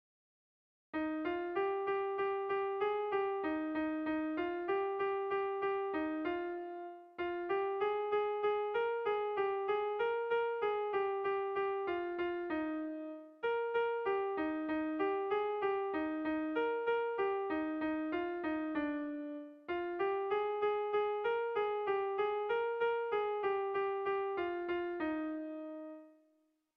Air de bertsos - Voir fiche   Pour savoir plus sur cette section
Zortziko handia (hg) / Lau puntuko handia (ip)
ABDB